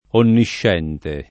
[ onnišš $ nte ]